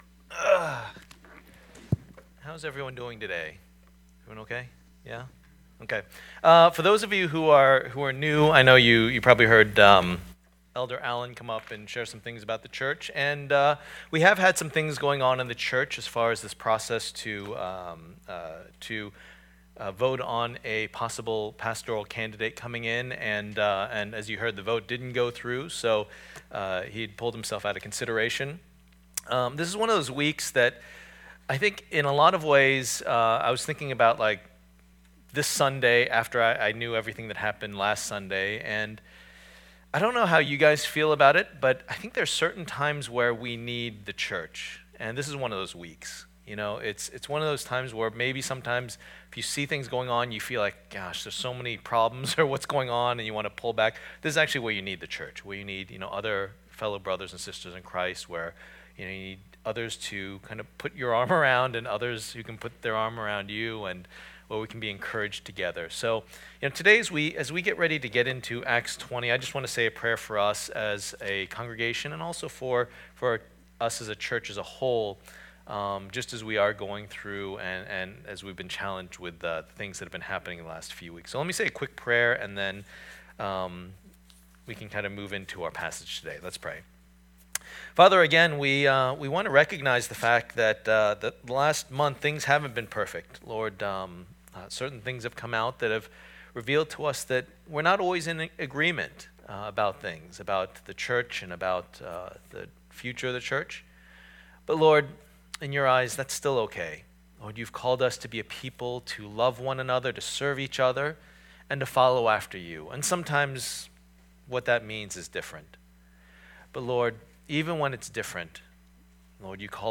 Series: A Study in the Book of Acts Passage: Acts 20:24-35 Service Type: Lord's Day %todo_render% « To An Unknown God Paul’s Defense